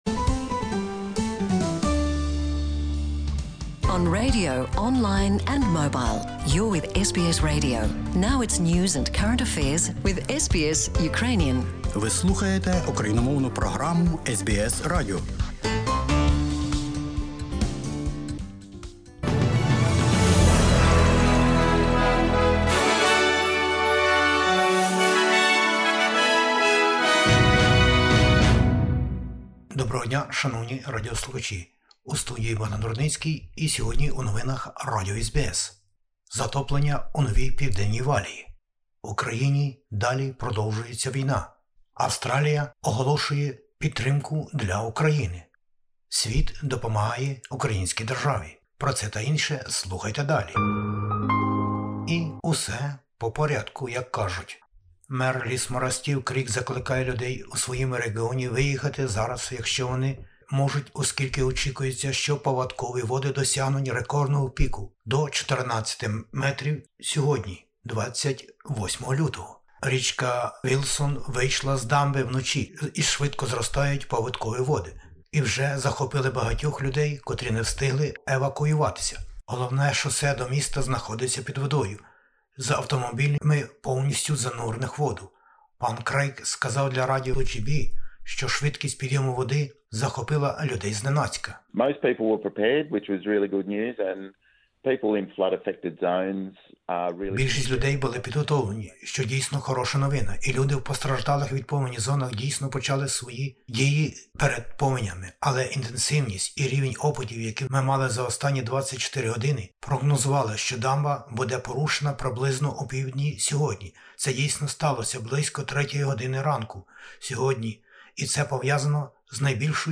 SBS бюлетень новин українською. Повені у НПВ і Квінсленді. Мер Лісмора Steve Krieg закликає людей у своєму реґіоні виїхати зараз, якщо вони зможуть, оскільки очікується, що паводкові води досягнуть рекордного піку в 14 метрів сьогодні ввечері. Війна в Україні - Австралія долучається до допомоги.